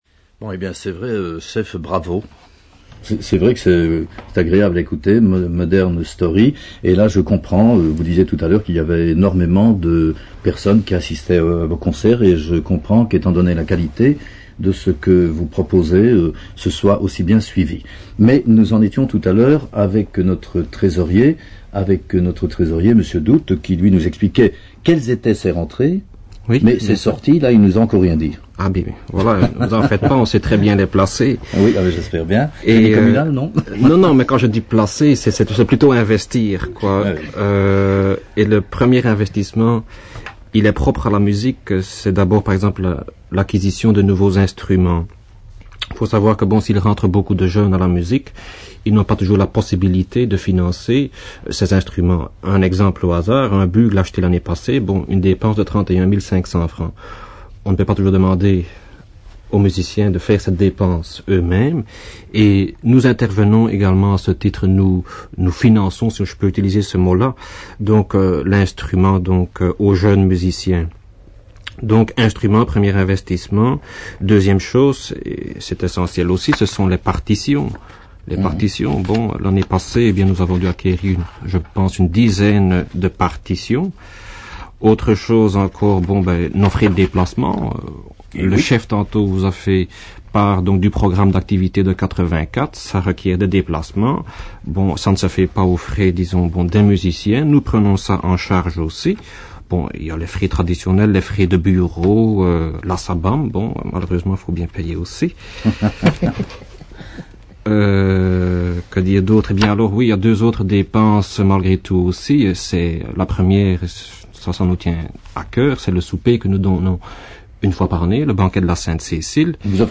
— Bon, eh bien, donc, nous continuerons tout à l'heure cet entretien, mais en attendant, nous allons écouter " Modern Story ", toujours une interprétation de la fanfare de Fraire.